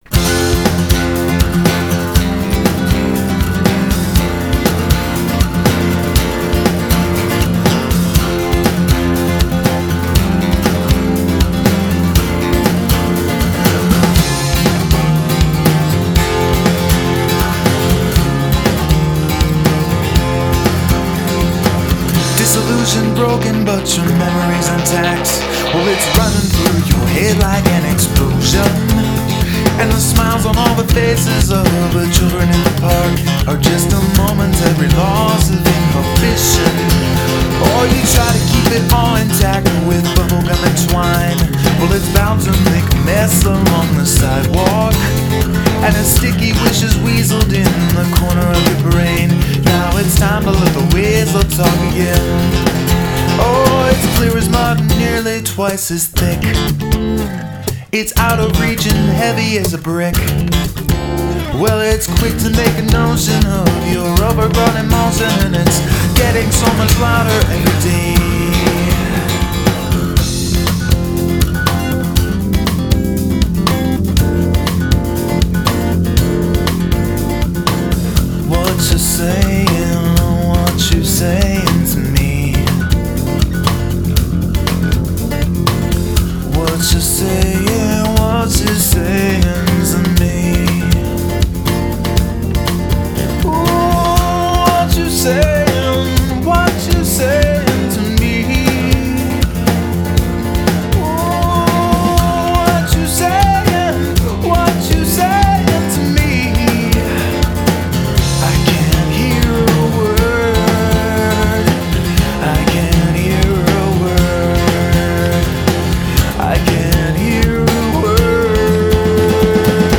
Very clear.